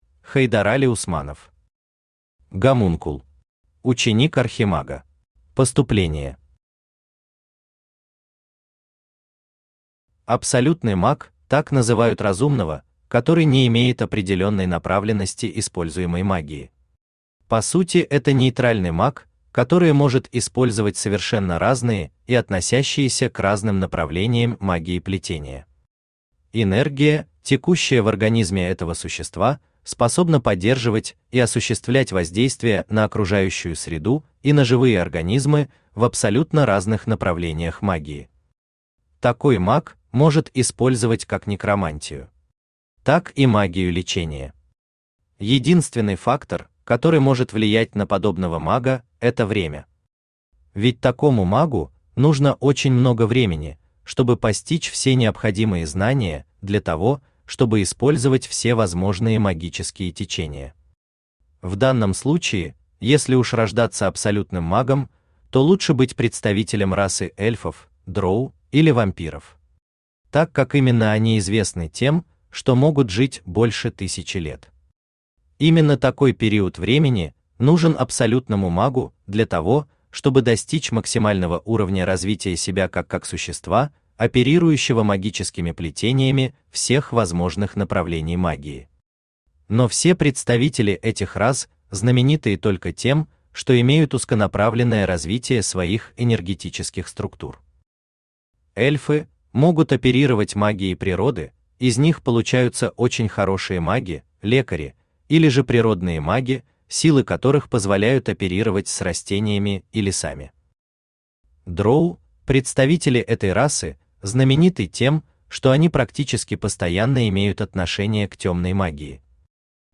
Ученик Архимага Автор Хайдарали Усманов Читает аудиокнигу Авточтец ЛитРес.